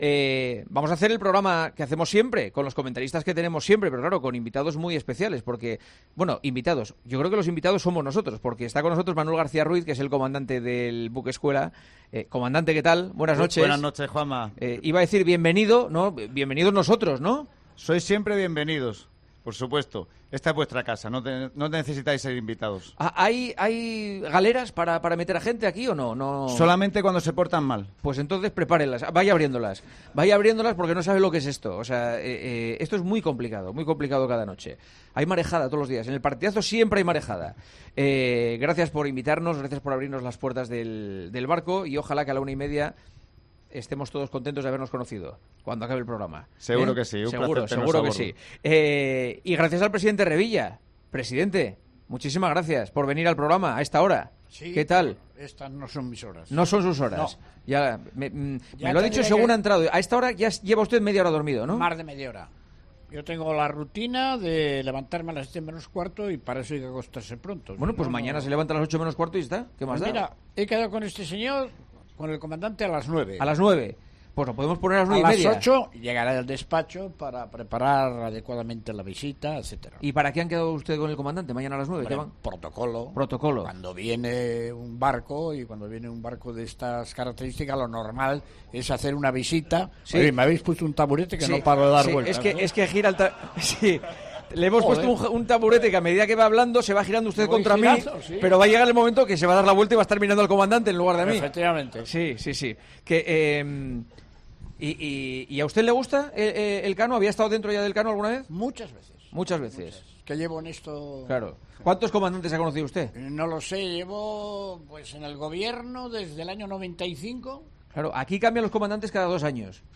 El presidente de Cantabria nos acompañó en el arranque del programa especial desde el Buque Escuela 'Juan Sebastián de Elcano', donde habló de su Racing y de la radio nocturna.